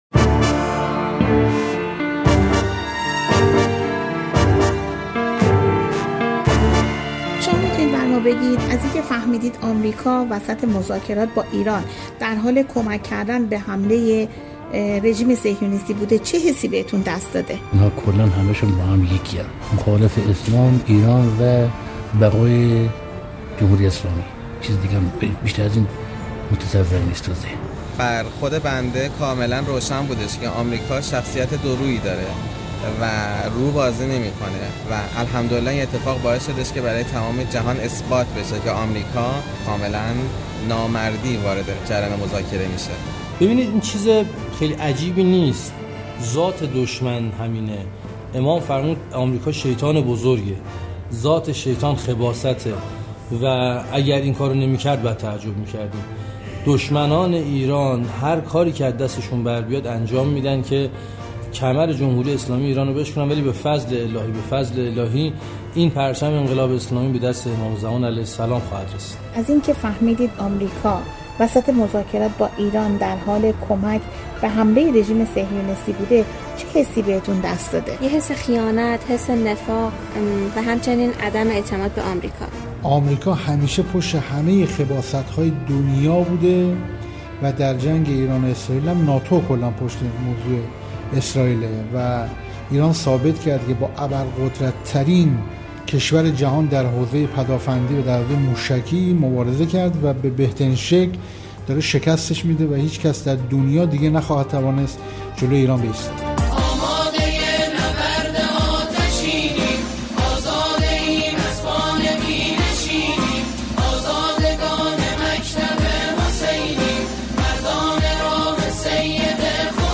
اهمیت پاسخ محکم به رژیم جعلی/ گفتگوی مردمی